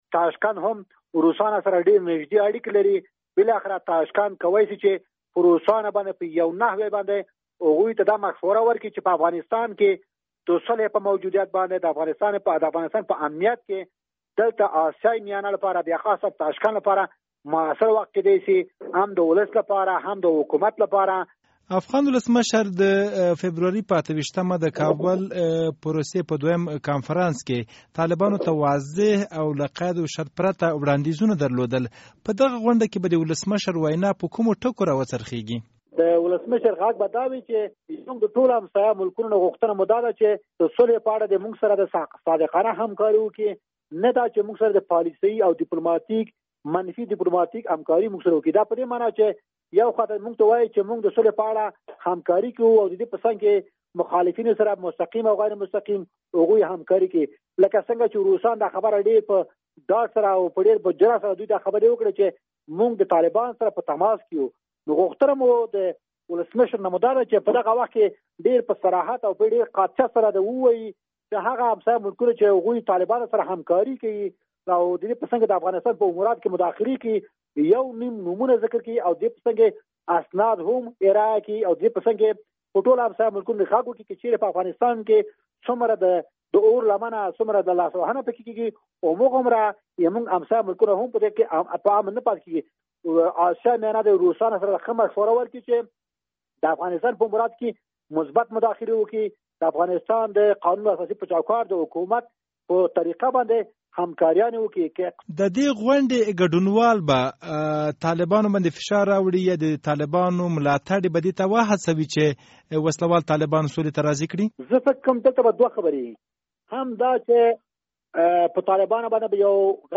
مرکه
له ښاغلي صدیقي سره مرکه